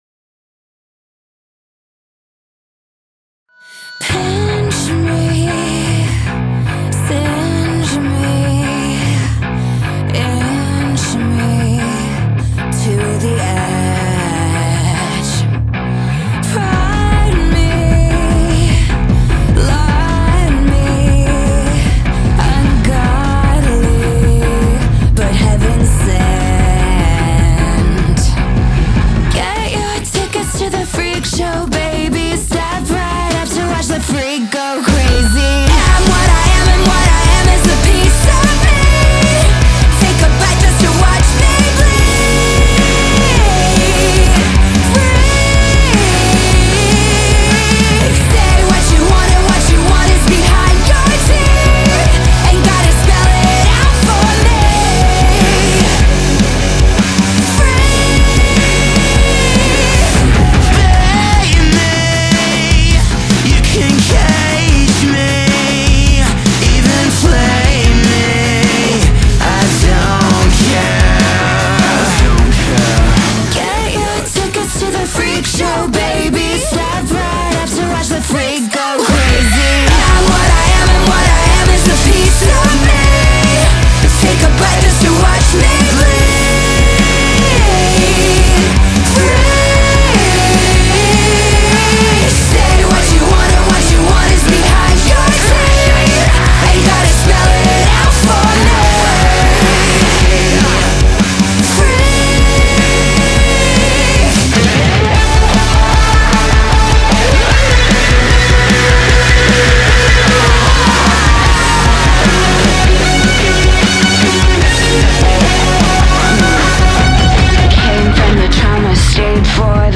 BPM152-152
Audio QualityPerfect (High Quality)
Pop Rock song for StepMania, ITGmania, Project Outfox
Full Length Song (not arcade length cut)